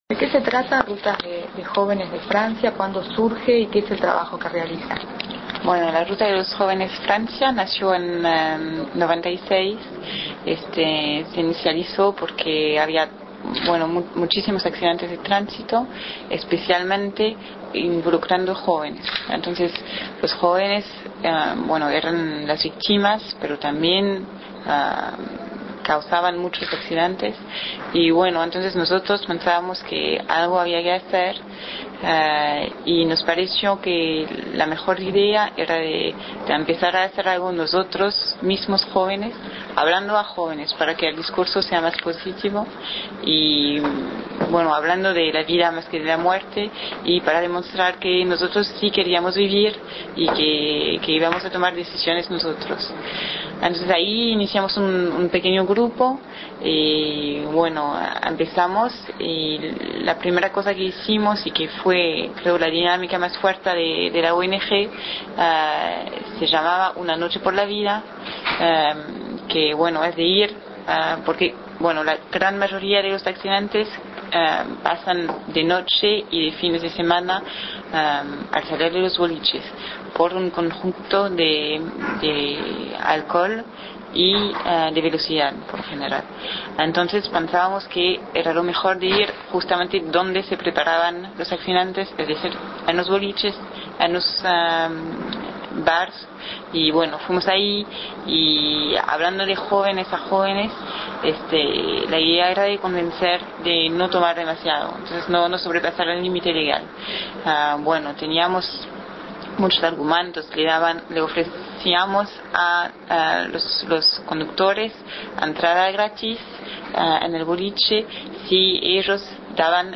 Declaraciones de la Ruta de los Jóvenes de Francia